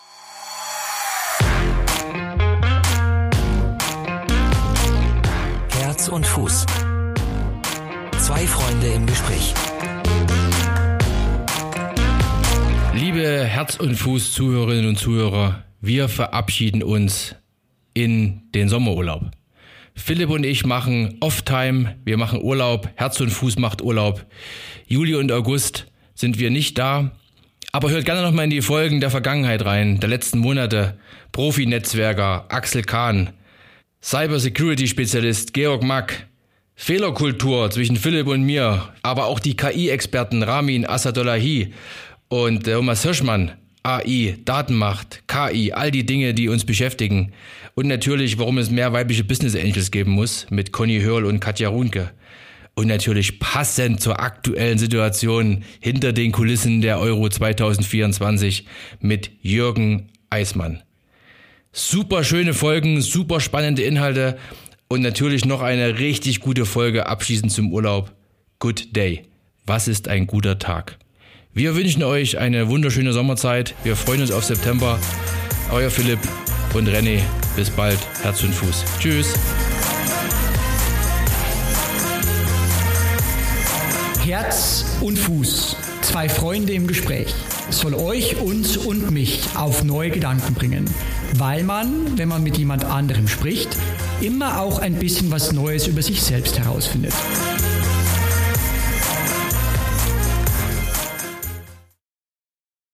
Zwei Freunde im Gespräch